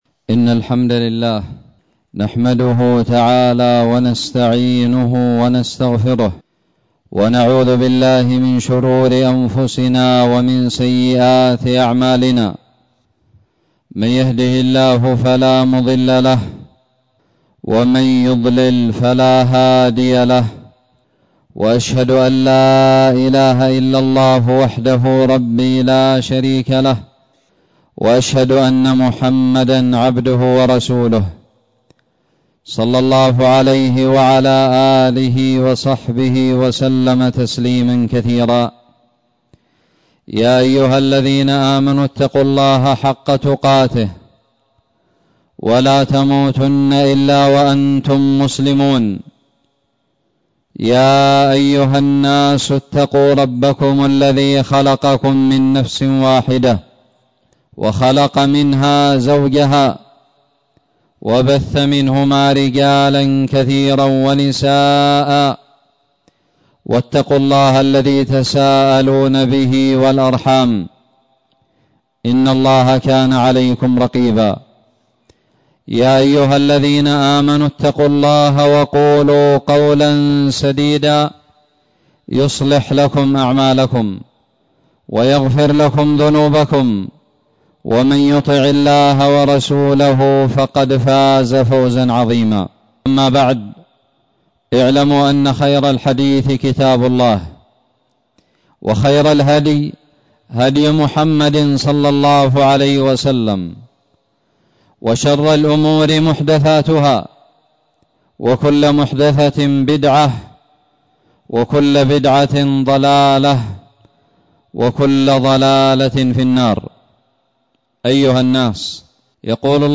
خطب الجمعة
ألقيت بدار الحديث السلفية للعلوم الشرعية بالضالع في 9 ذي القعدة 1440هــ